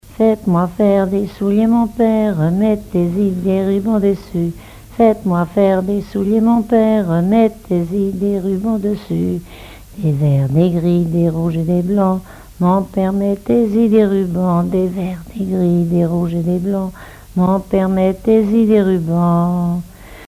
branle
collecte en Vendée
Témoignages et chansons traditionnelles
Pièce musicale inédite